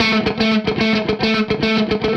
AM_HeroGuitar_110-A01.wav